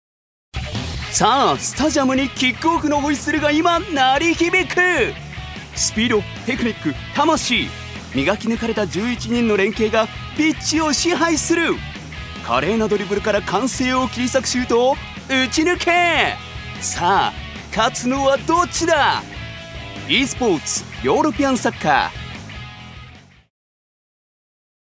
所属：男性タレント
ナレーション６